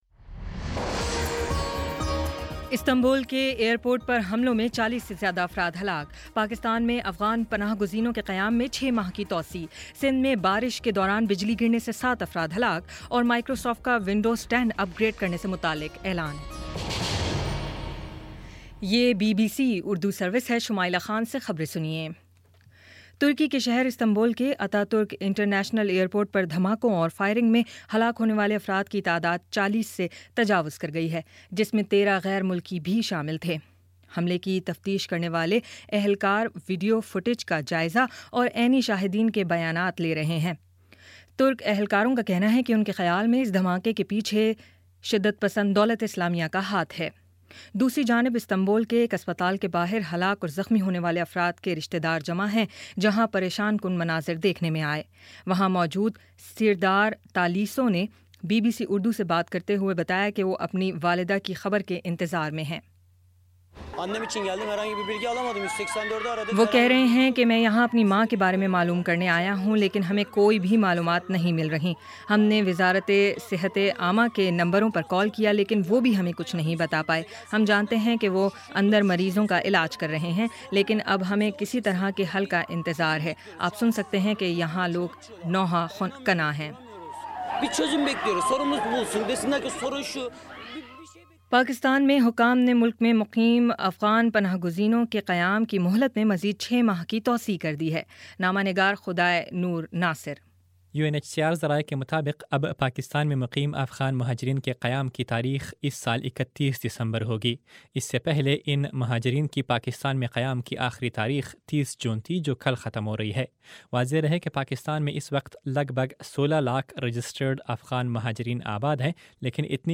جون 29 : شام پانچ بجے کا نیوز بُلیٹن